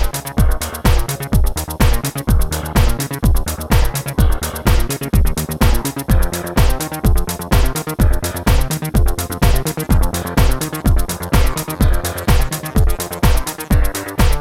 no Backing Vocals Disco 1:26 Buy £1.50